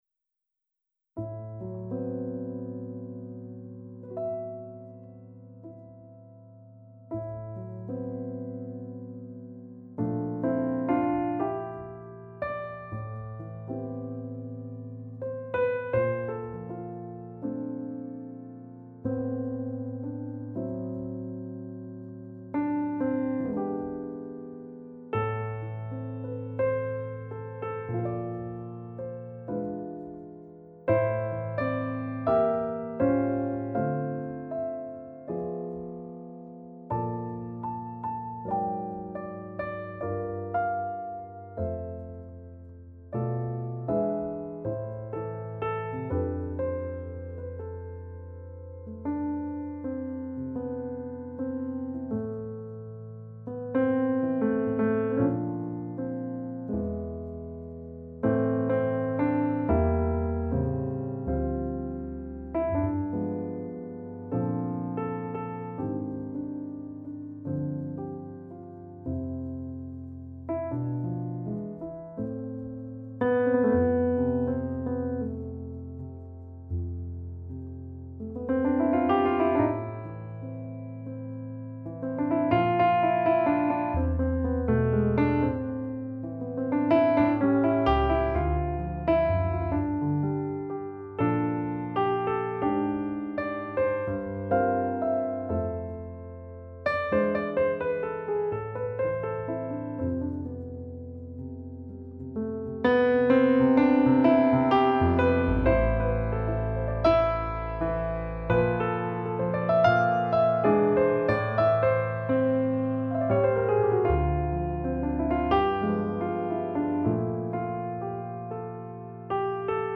Jazz version